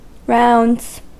Ääntäminen
France: IPA: [ɛ̃ tuʁ]